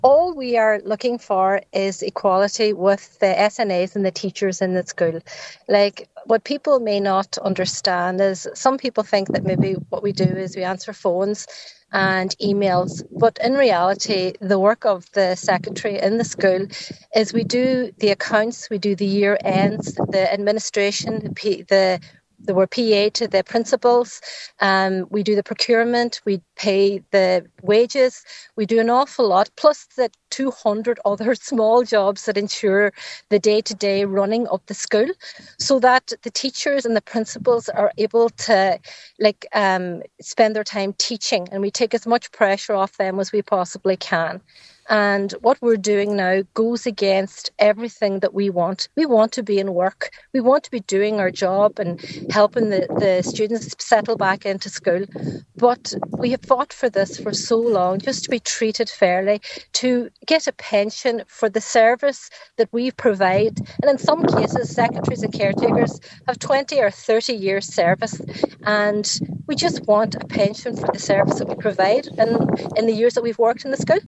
School secretaries speak from the picket line